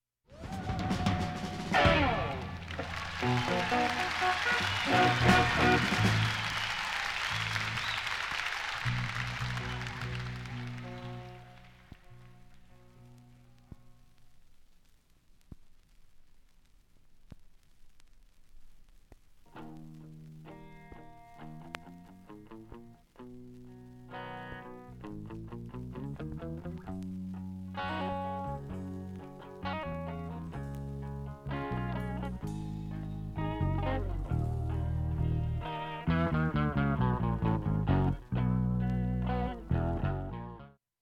音質良好全曲試聴済み。
プレス時の付着物でかすかなプツが１５回出ますが
聴き取り出来るか不安なレベルです。
ほかB-２中盤に静かな部に２回プツ出ます。